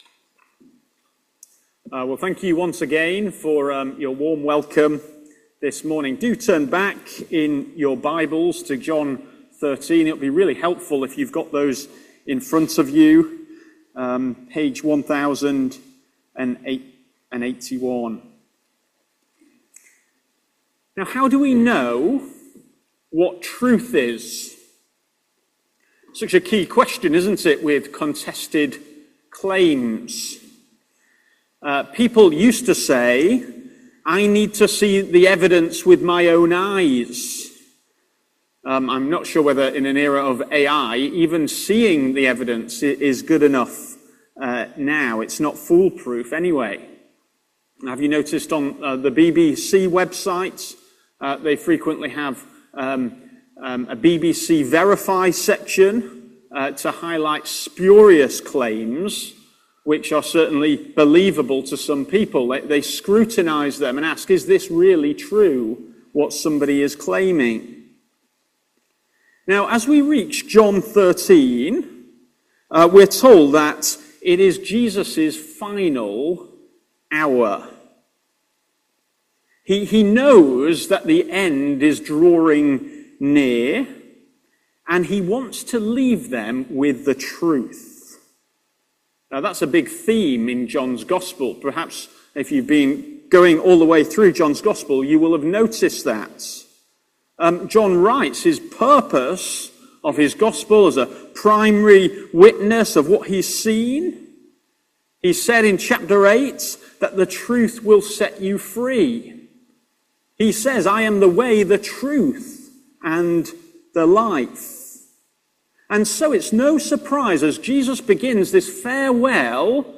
No reading recorded; apologies